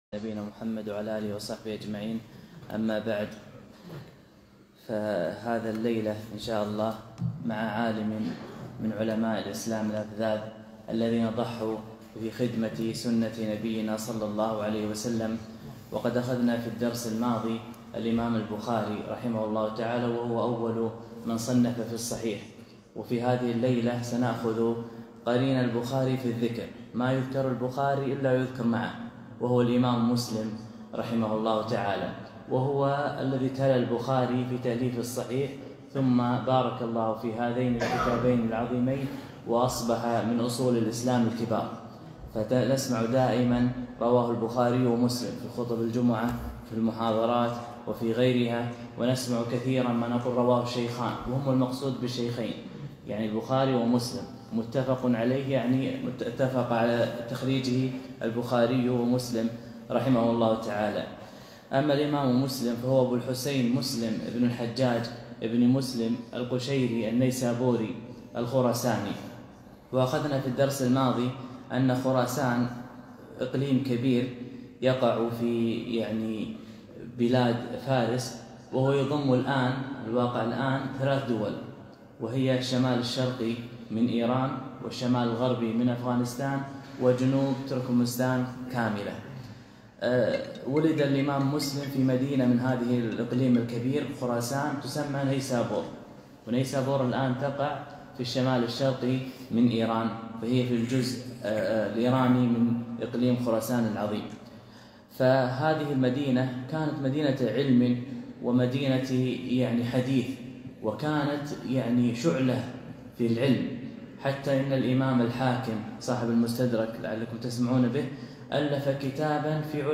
محاضرة - سيرة الإمام مسلم (رحمه الله)